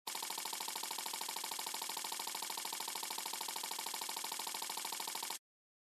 reels.mp3